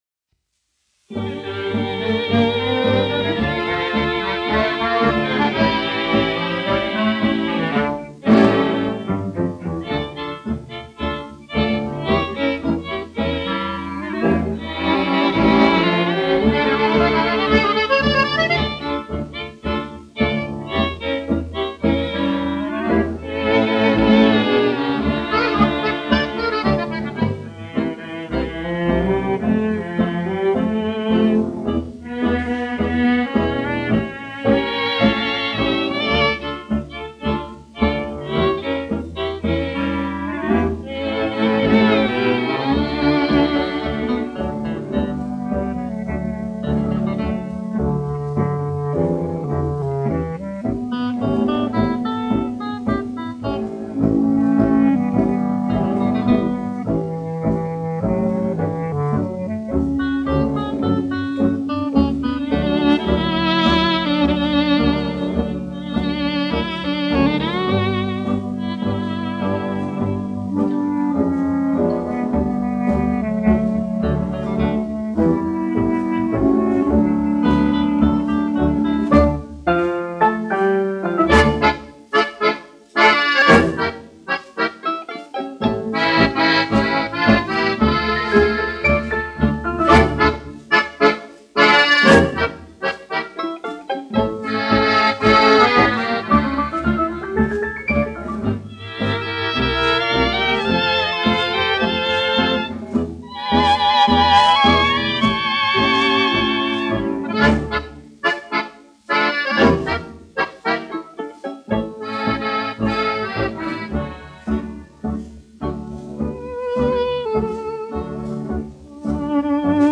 1 skpl. : analogs, 78 apgr/min, mono ; 25 cm
Populārā instrumentālā mūzika
Skaņuplate
Latvijas vēsturiskie šellaka skaņuplašu ieraksti (Kolekcija)